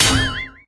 bibibat_impact_01.ogg